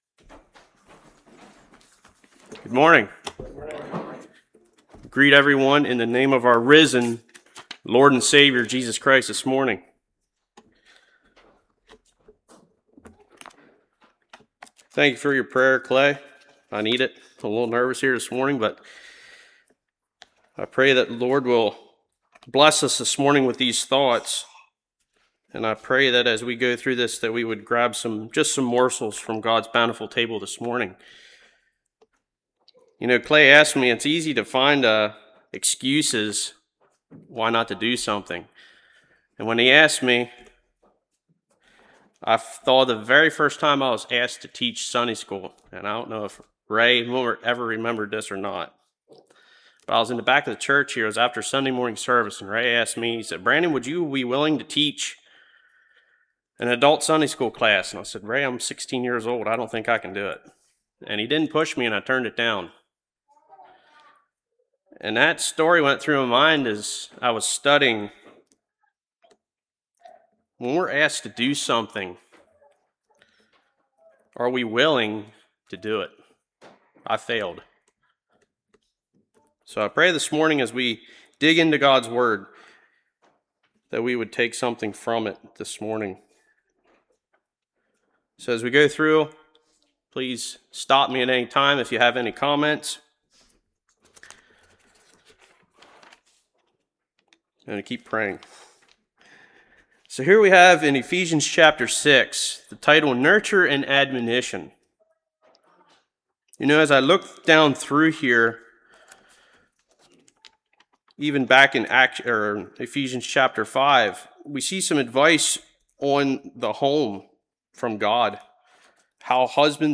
Ephesians 6:1-9 Service Type: Sunday School Teach by example